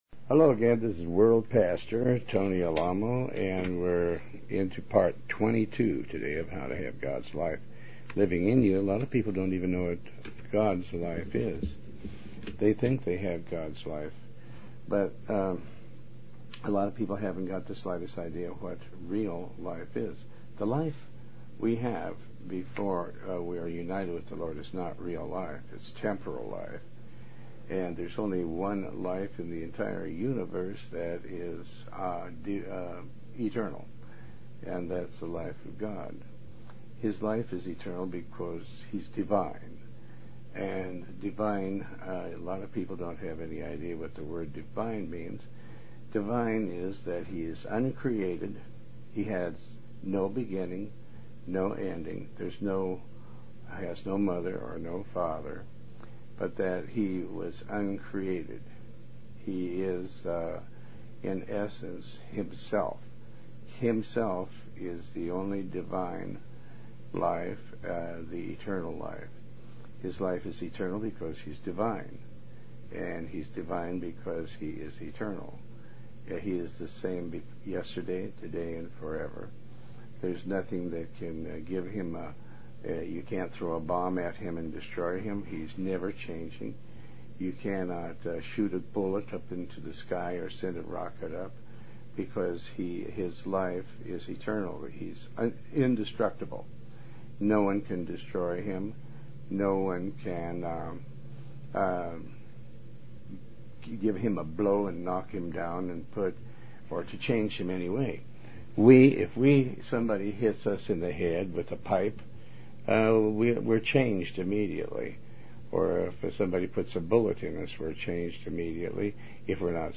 Talk Show Episode, Audio Podcast, Tony Alamo and Ep124 How To Have Gods Life Living In You Part 22 on , show guests , about How To Have Gods Life Living In You,pastor tony alamo,Tony Alamo Christian Ministries,Faith,Bible Study, categorized as Health & Lifestyle,History,Love & Relationships,Philosophy,Psychology,Christianity,Inspirational,Motivational,Society and Culture